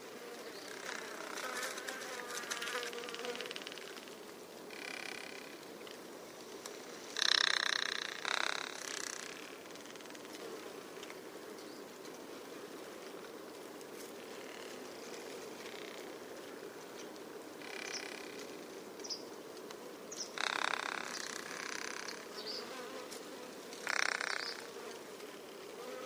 Original creative-commons licensed sounds for DJ's and music producers, recorded with high quality studio microphones.
wolf spider drumming.wav
wolf_spider_drumming-2_Em8.wav